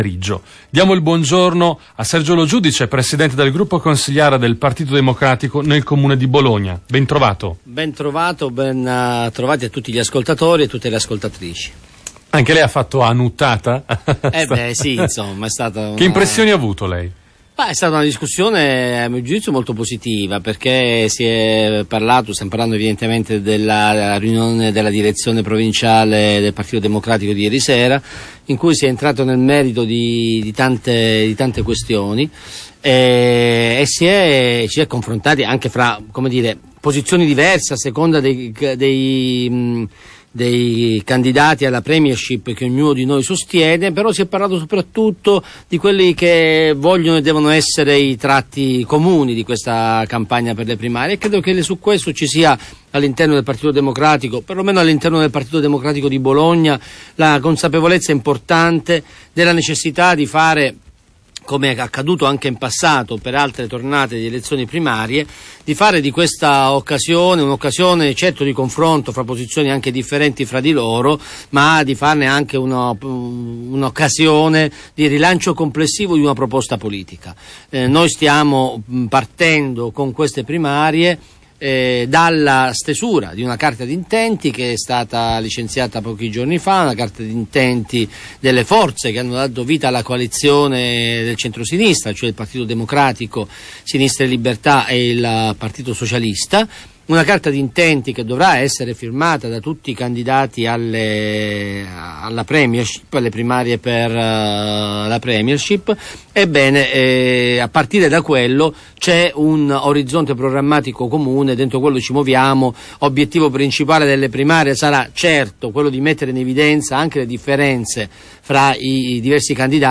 Attualit� politica: il capogruppo PD Sergio Lo Giudice in un'intervista a Radio Tau - 16 ottobre 2012